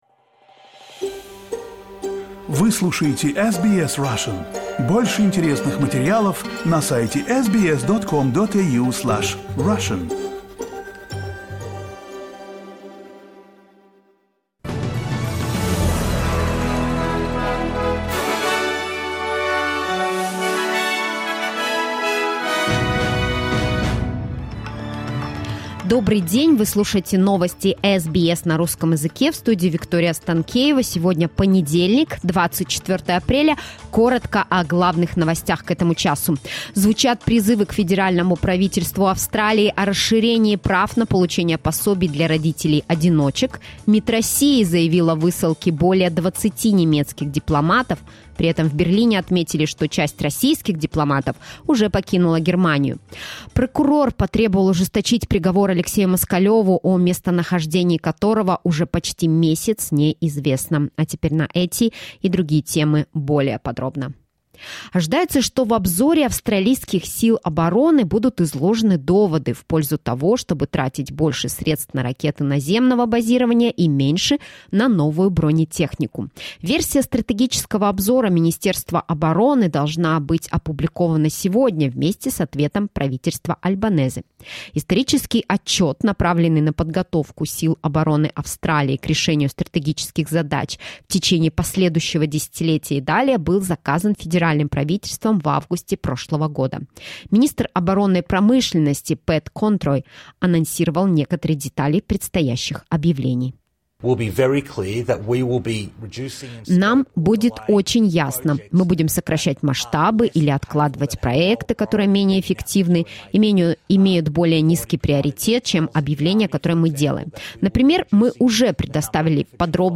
SBS news in Russian — 24.04.2023